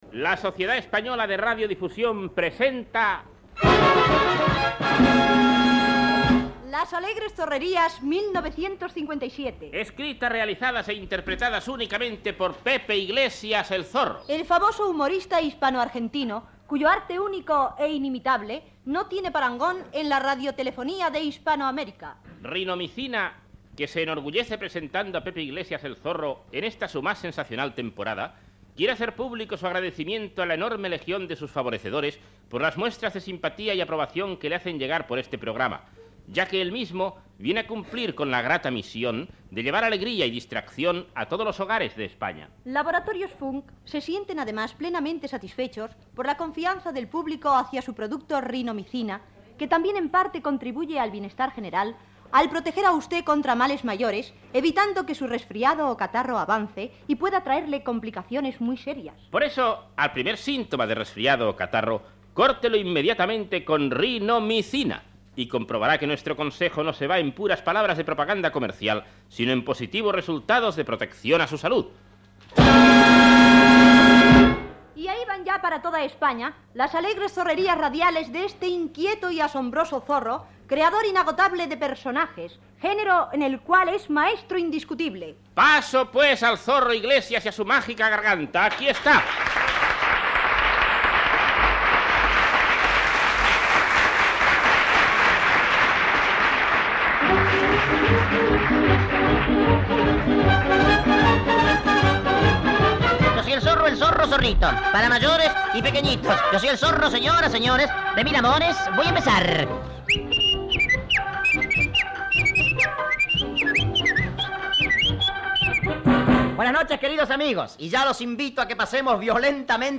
Presentació del programa, publicitat, cançó "Yo soy el zorro", presentació de l'Hotel La Sola Cama
Entreteniment